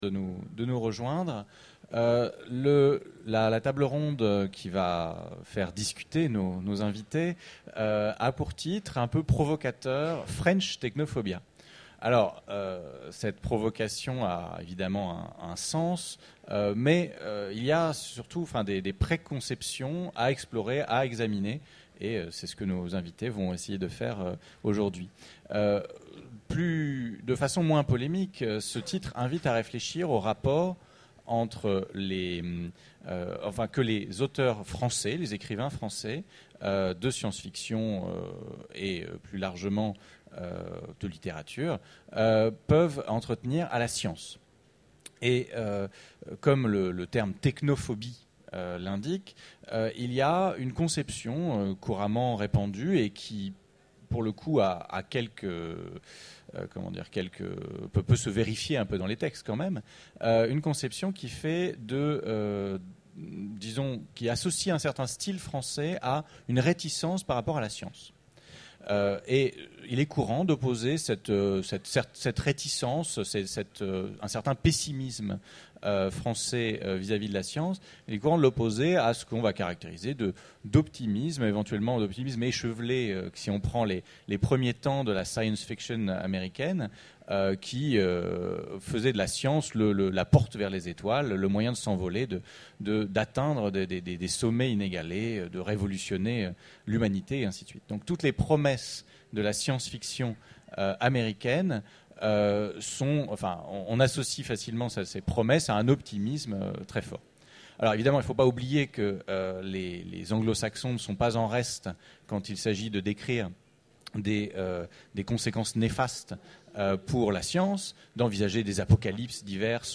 Utopiales 12 : Conférence French Technophobia